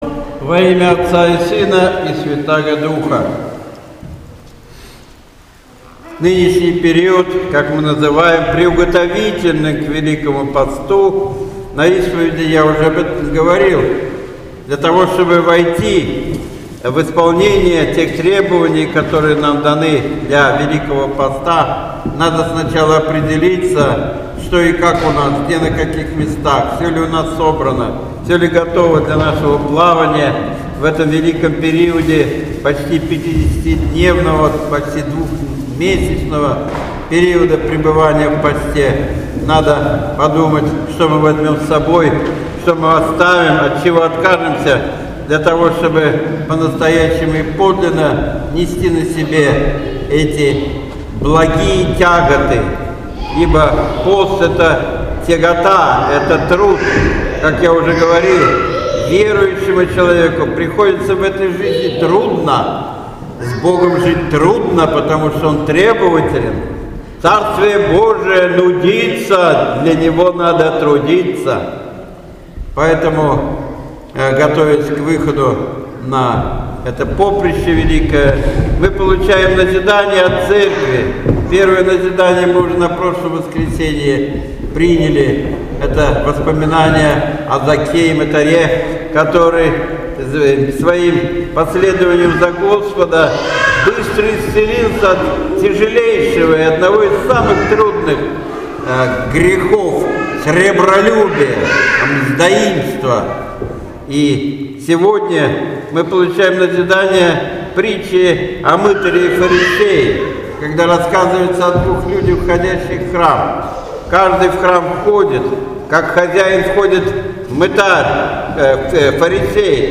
Божественная Литургия 5 февраля 2017 года.